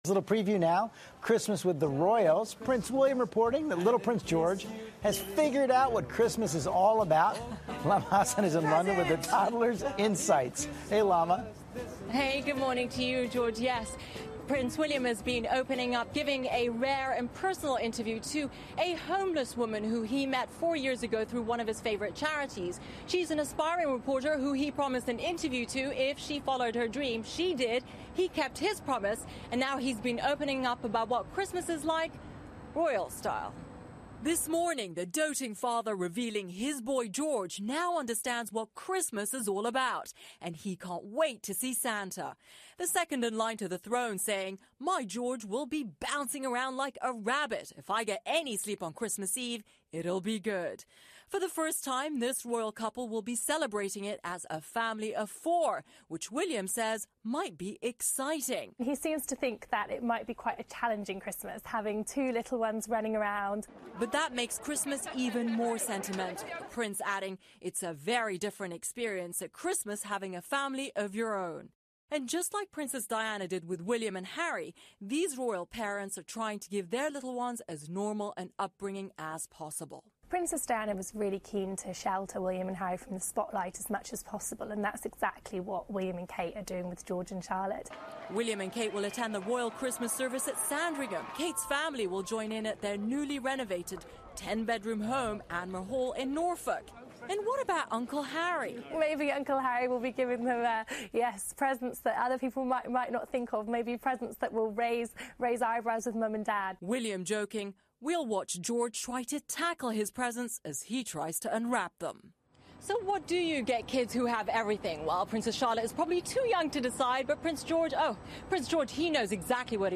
访谈录 威廉王子聊皇室的圣诞节计划 乔治小王子特兴奋 听力文件下载—在线英语听力室